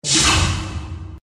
liftdoor.mp3.svn-base